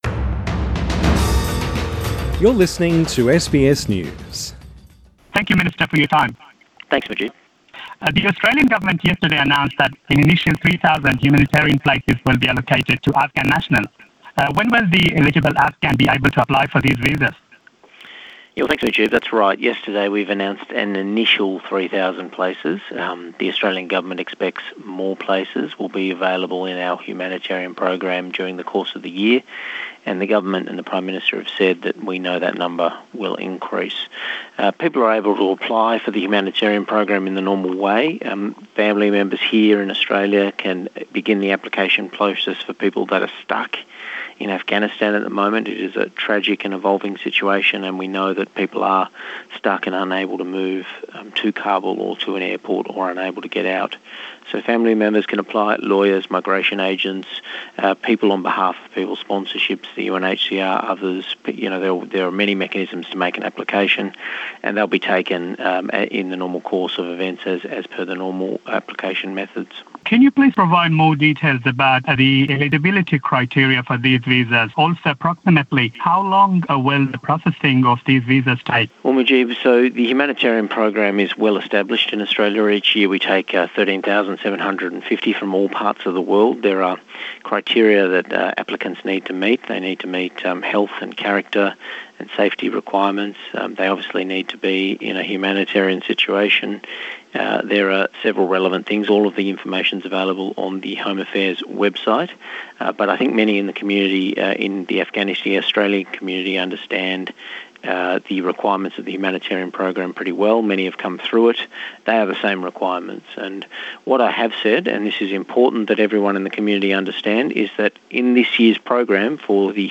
Please listen to the full interview in English.